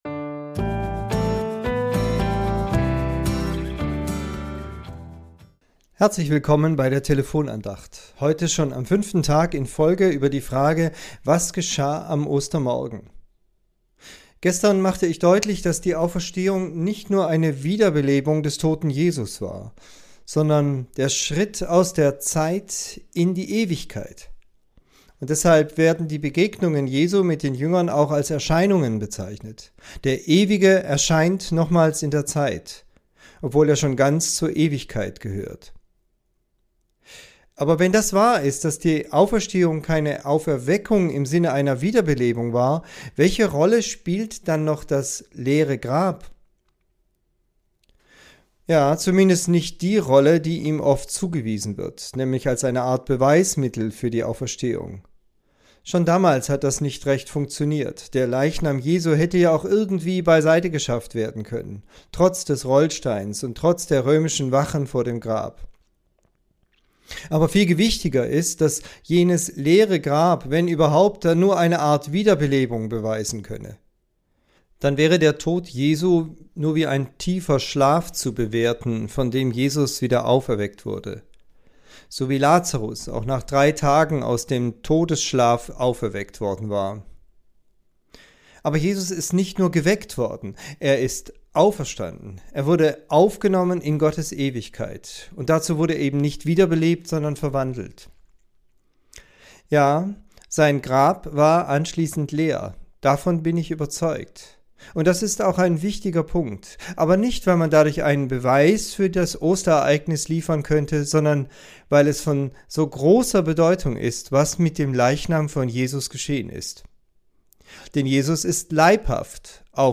Tübinger Telefonandacht zur Tageslosung